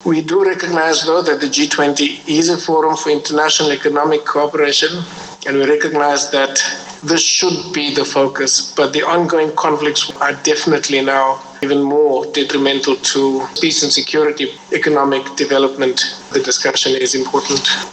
Director-General of International Relations and Cooperation Zane Dangor In his opening remarks, Dangor stressed the need for concrete, practical solutions and urged Sherpas to address growing geopolitical tensions alongside core economic issues.